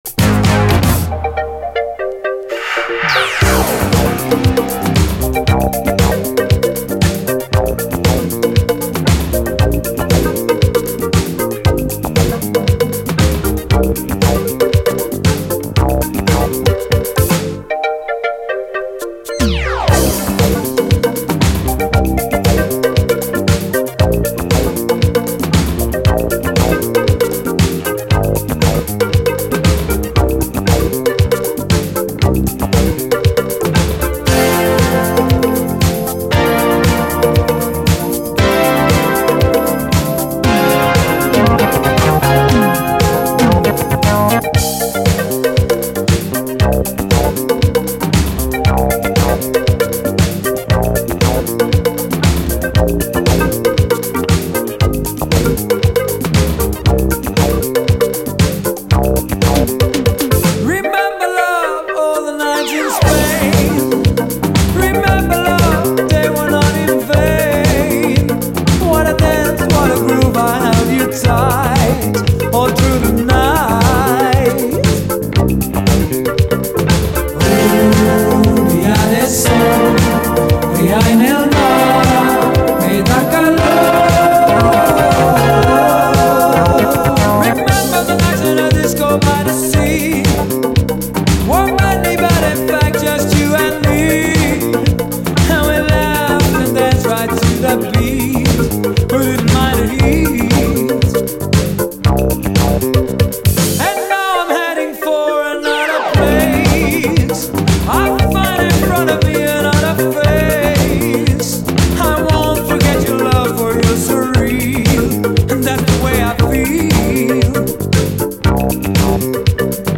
DISCO
ユーロ産の妖しいトロピカル・シンセ・ディスコ〜イタロ・ディスコ！